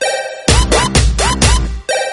Tono de llamada Música clásica